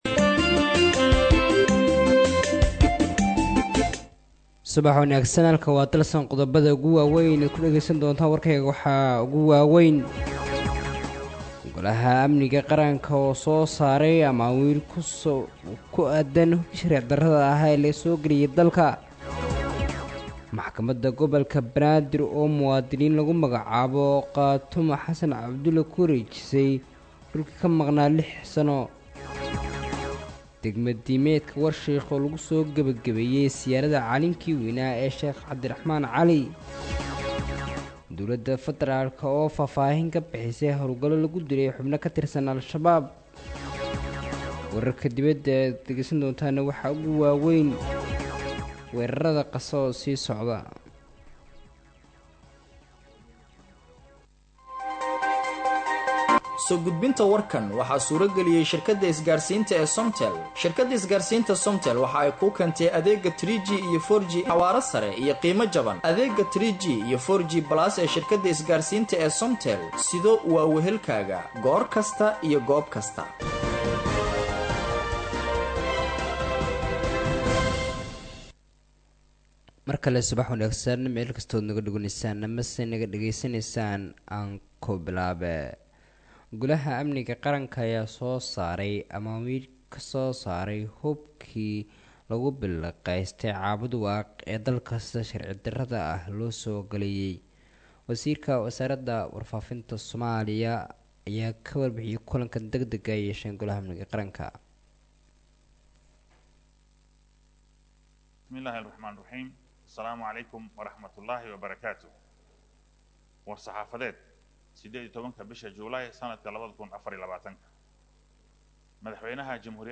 Dhageyso:-Warka Subaxnimo Ee Radio Dalsan 19/07/2024